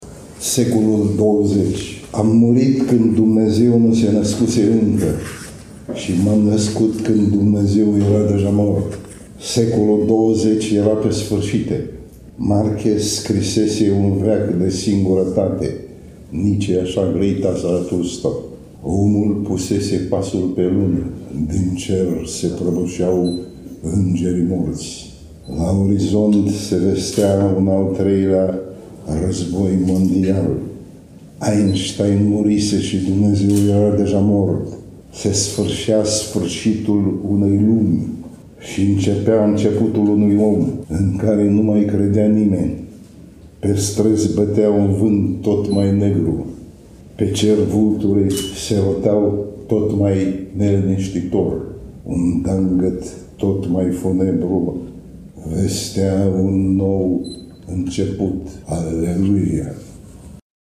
Vă invit, din nou alături de vocea poetului Nichita Danilov. De data aceasta ne recită Secolul XX.